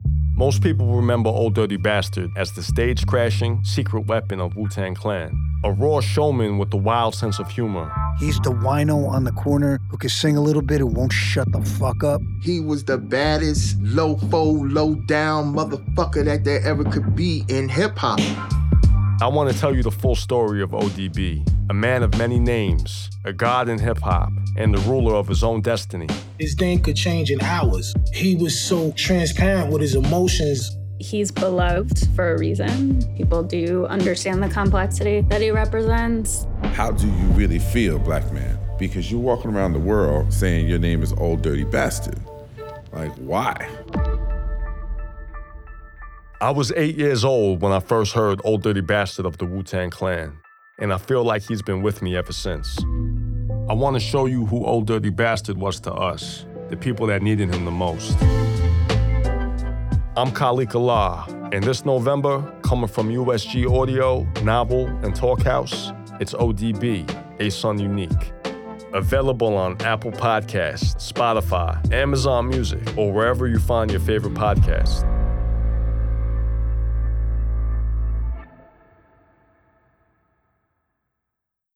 Here’s the podcast’s audio trailer:
ODB-A-Son-Unique-Trailer.wav